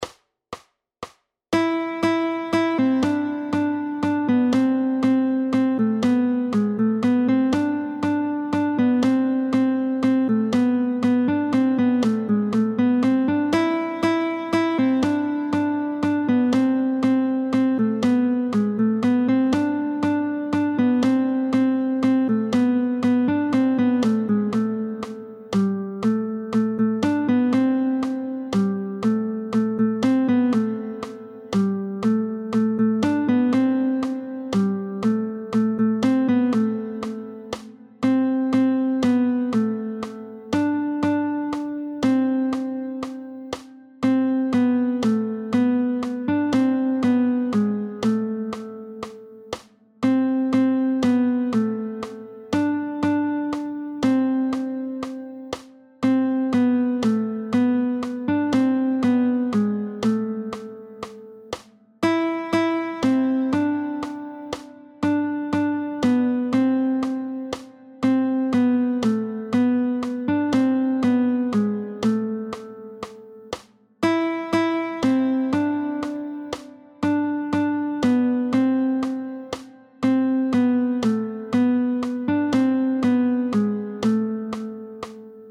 √ برای ساز گیتار | سطح آسان
همراه 3 فایل صوتی برای تمرین هنرجویان